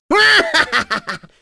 Lakrak-Vox_Happy3.wav